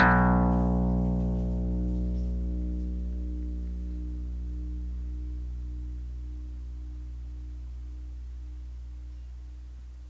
Le chevalet de ma guitare ne permettait pas ce dégagement d’harmoniques, tout au contraire, il avait été conçu pour qu’on les entende le moins possible.
Chevalet « anglé  » (comme sur les guitares, les violons, les violoncelles…)
6-SON-CHEVALET-22ANGLE22.m4a